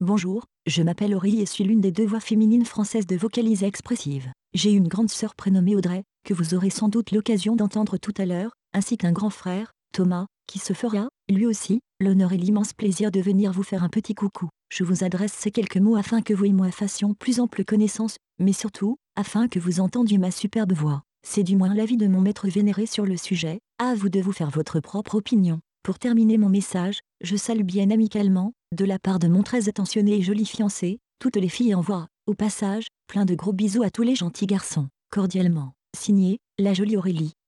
Texte de démonstration lu par Aurélie, voix féminine française de Vocalizer Expressive
Écouter la démonstration d'Aurélie, voix féminine française de Vocalizer Expressive